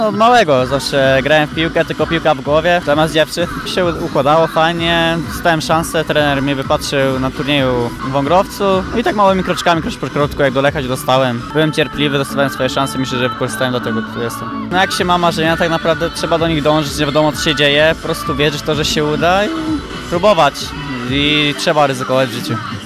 Podczas zmagań, kolegów ze swojego dawnego zespołu dopingował Karol Linetty – obecny zawodnik Lecha Poznań. Udało nam się zamienić z nim kilka słów.